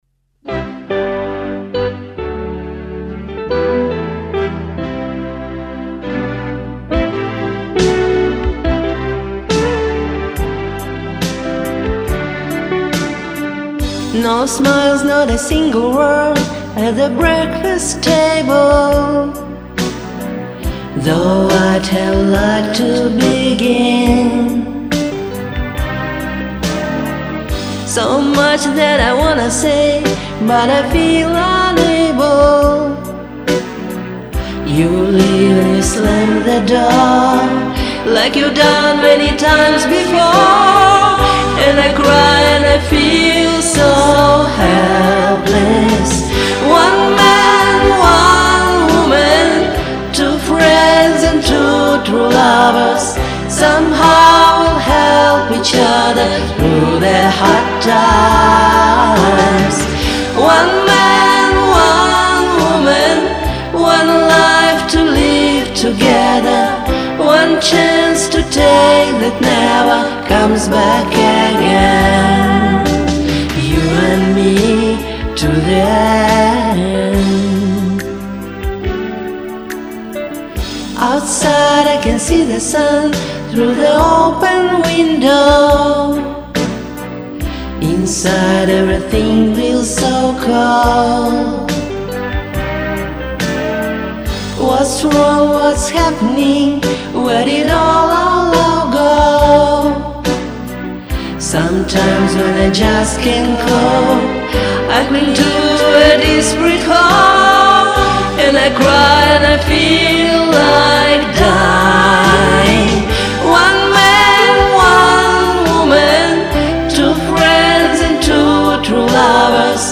Да-да. Зря я спела эти бэки.
Первый голос выпирает слегка.